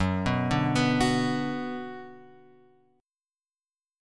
F#sus4#5 chord {2 2 0 x 0 2} chord